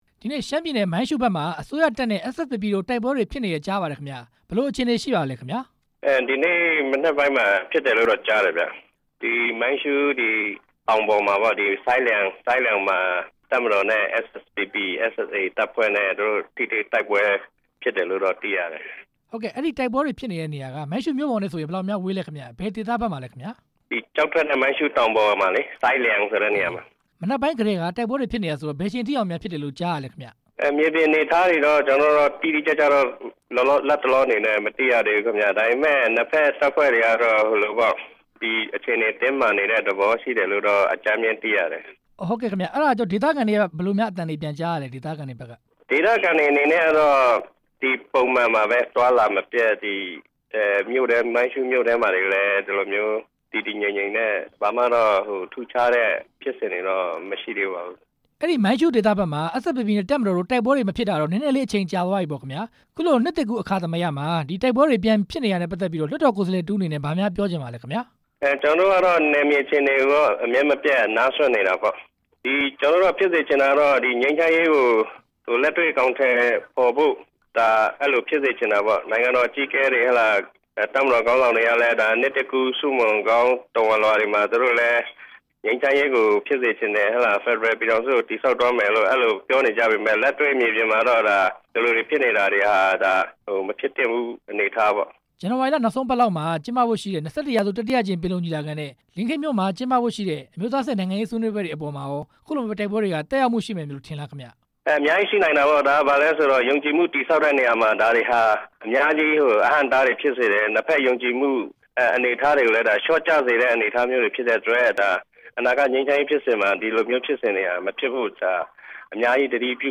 အစိုးရတပ်မတော်နဲ့ SSPP/SSA တို့ တိုက်ပွဲဖြစ်တဲ့အကြောင်း မေးမြန်းချက်